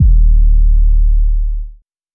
Gang Gang 808.wav